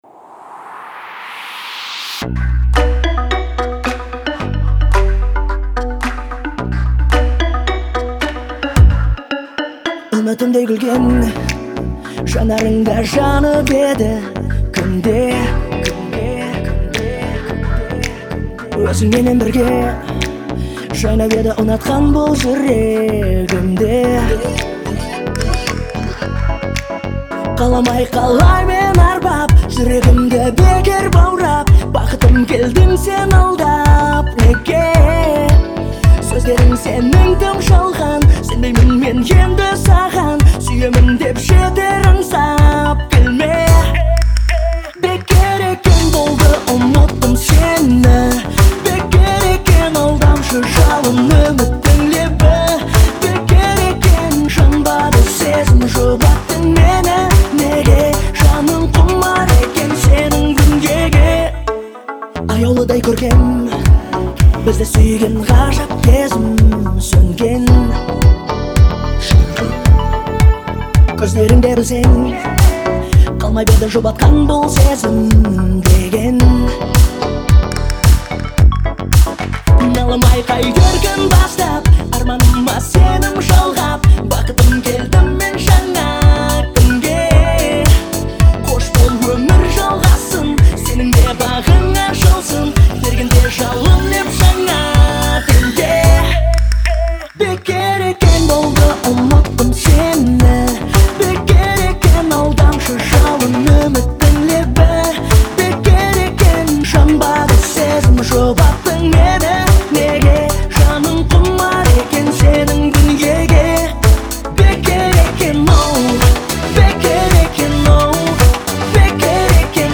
это яркая композиция в жанре казахского поп-фолка